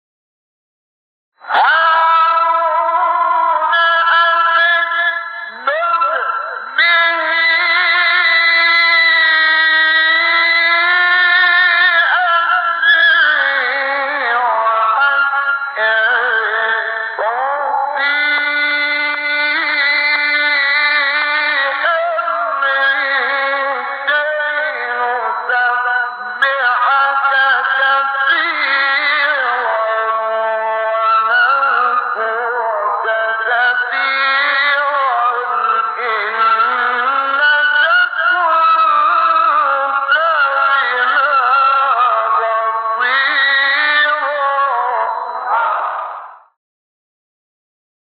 سایت قرآن کلام نورانی - سه گاه انور شحات جواب جواب (2).mp3